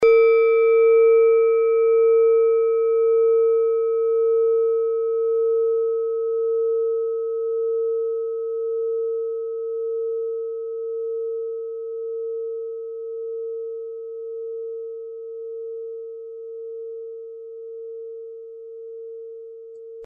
Klangschale Nepal Nr.7
Klangschale-Gewicht: 810g
Klangschale-Durchmesser: 14,2cm
(Ermittelt mit dem Filzklöppel)
Der Ton des Siderischen Mondes liegt bei 227,43 Hz, das ist auf unserer Tonleiter nahe beim "B".
klangschale-nepal-7.mp3